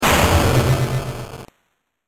جلوه های صوتی
دانلود صدای بمب 13 از ساعد نیوز با لینک مستقیم و کیفیت بالا